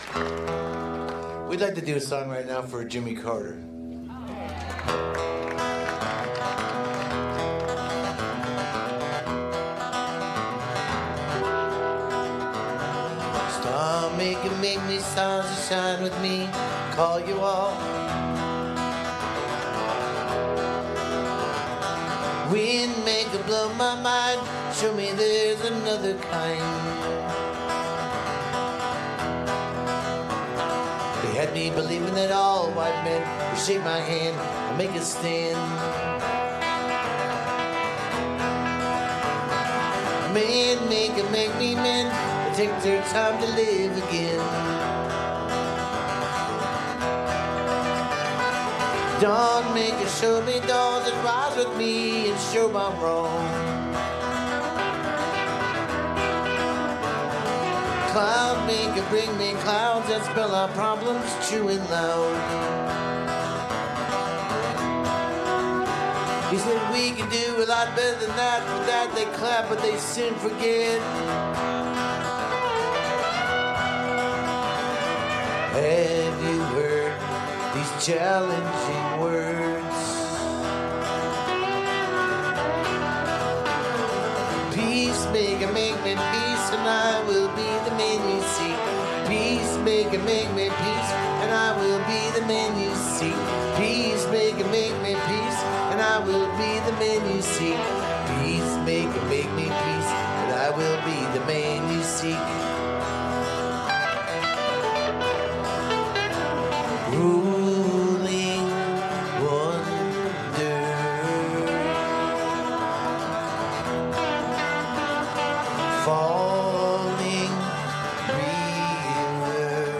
(captured from the live stream)